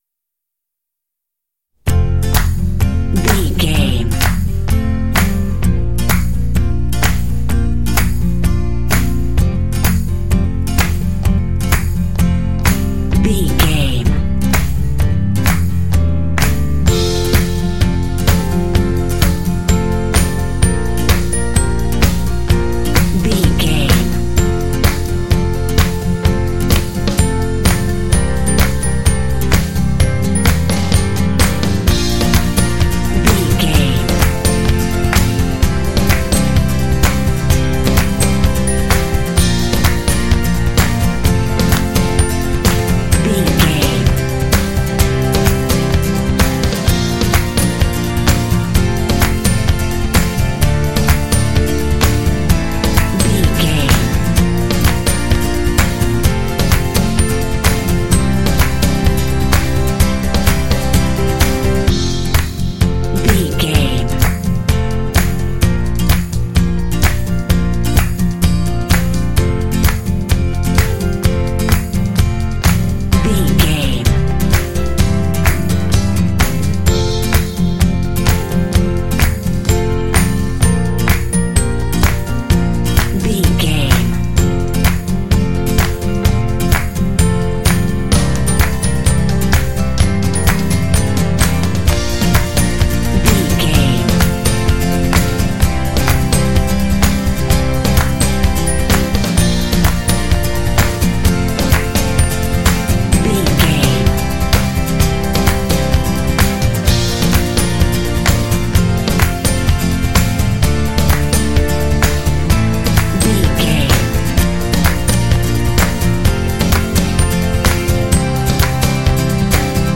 Uplifting
Ionian/Major
positive
happy
fun
acoustic guitar
piano
drums
percussion
bass guitar
strings
pop
alternative